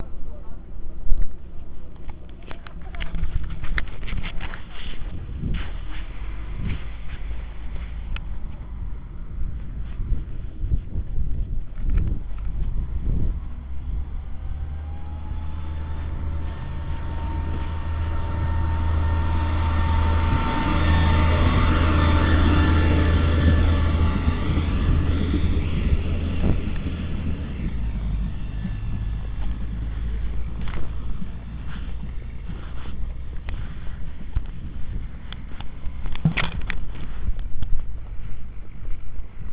Zde máte hvízdající dnes čerstvě zprovozněnou 814.007
Dejte si zvuk na maximum jinak nic neuslyšíte. Samotné hvízdání je až od 00:13 sec asi po 00:28 sec. Dělá to snad jen tahle naše Regionova 007.